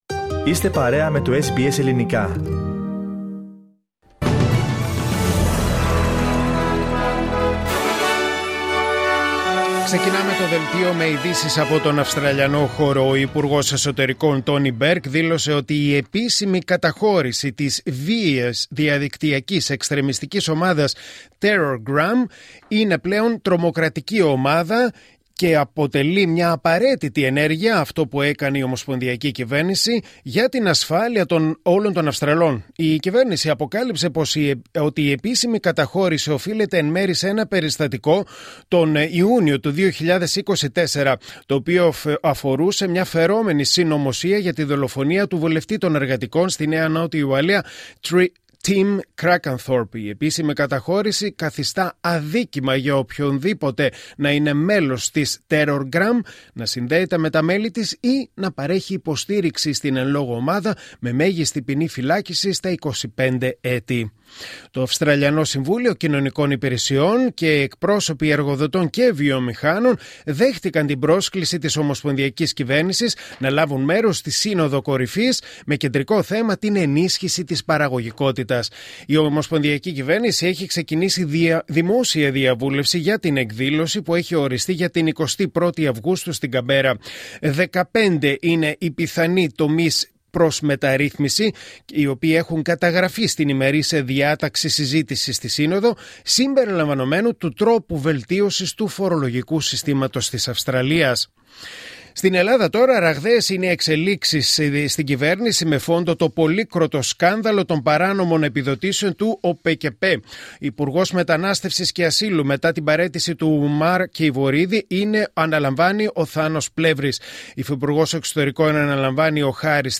Δελτίο Ειδήσεων Κυριακή 29 Ιουνίου 2025